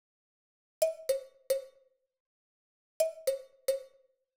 35 Agogos.wav